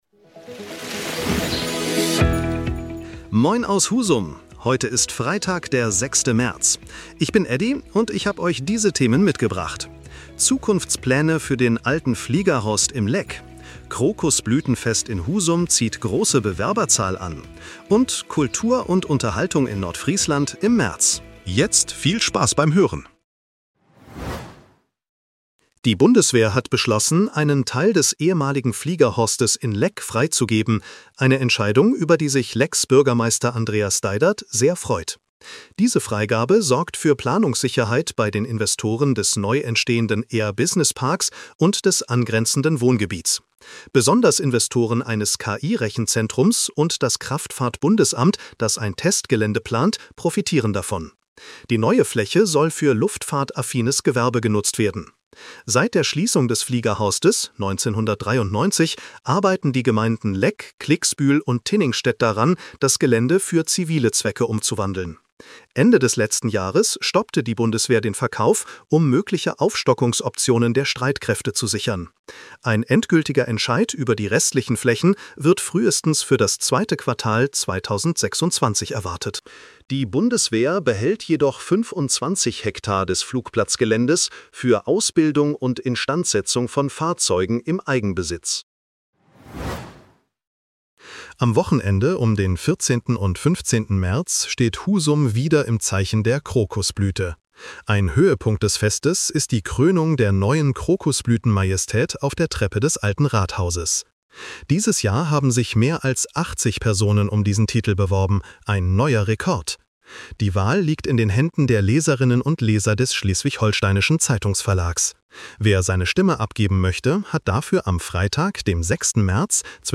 In unserem regionalen Nachrichten-Podcast